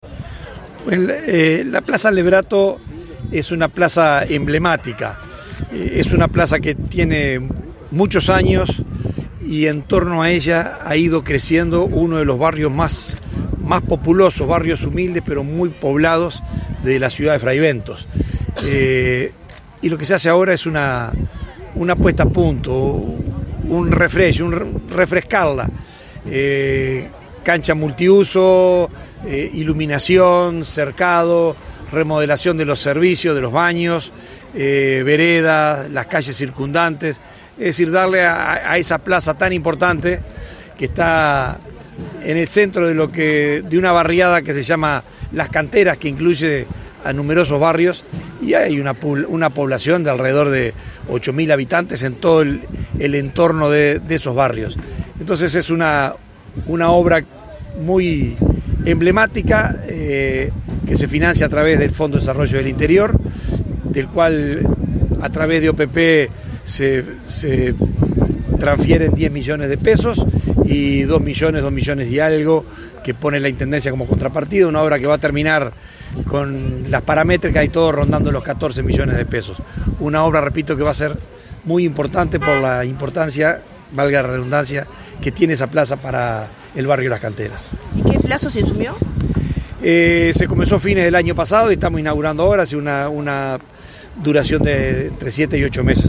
Declaraciones del subdirector de la OPP, Benjamín Irazabal
El subdirector la OPP, Benjamín Irazabal, explicó el alcance de la iniciativa.